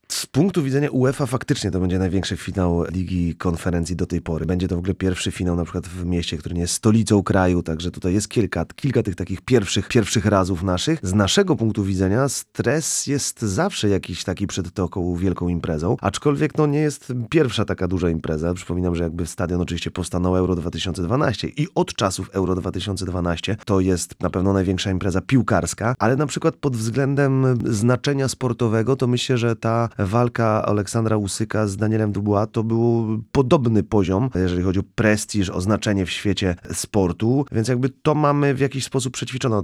Cała rozmowa w audycji „Sportowy kwadrans” dziś o 15:30!